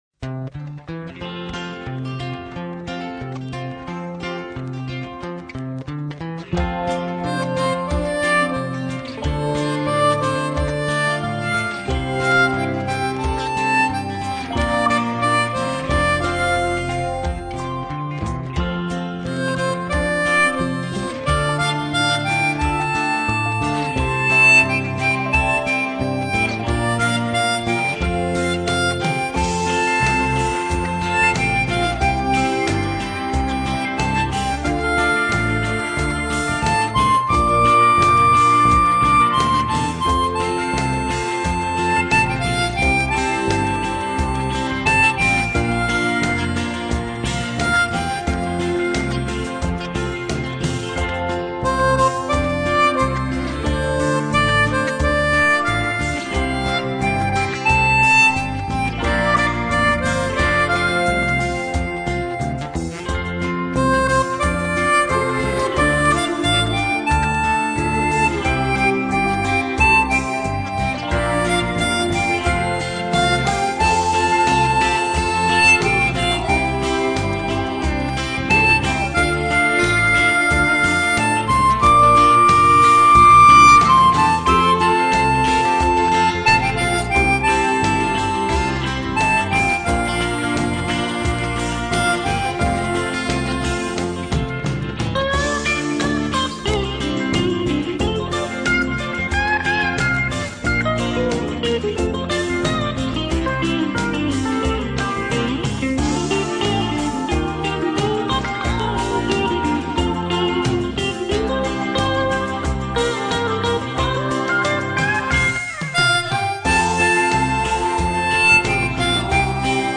Et puis, un peu de country ça fait du bien !!!
Version diato